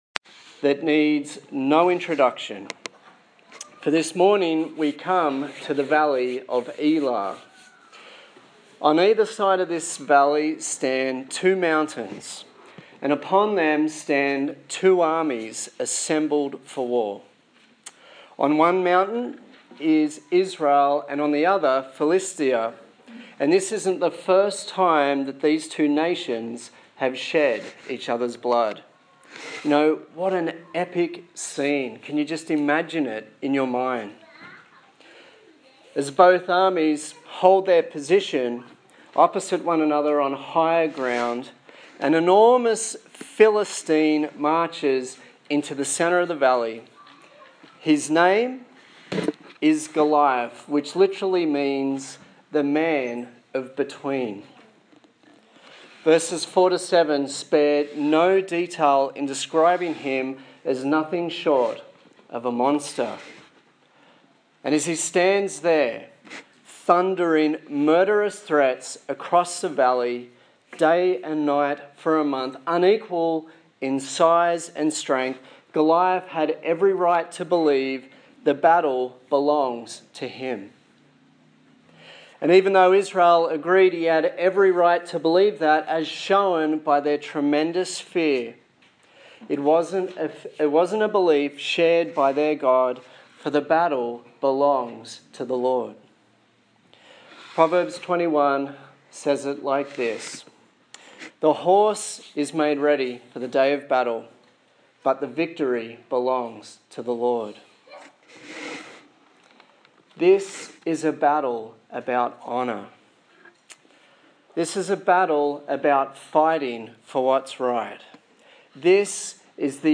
15/11/2015 The Battle Belongs to the Lord Preacher
Service Type: Sunday Morning